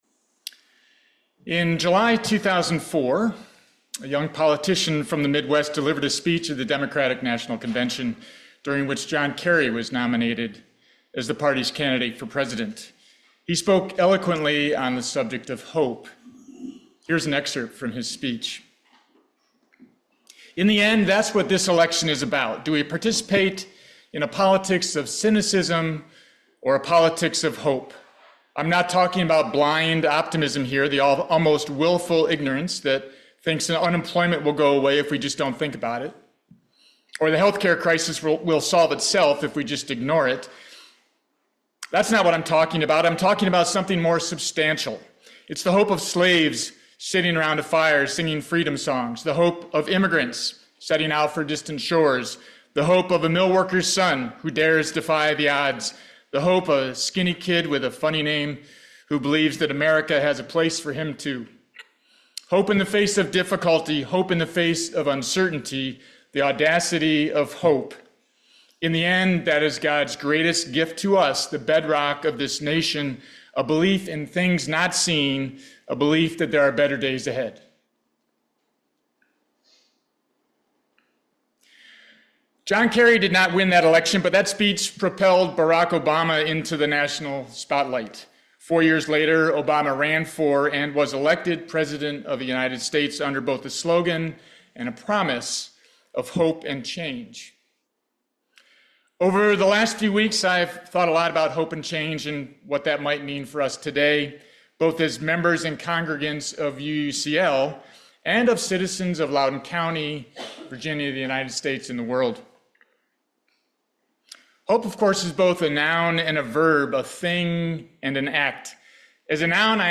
This sermon explores the essential link between hope and meaningful change in both personal and societal contexts.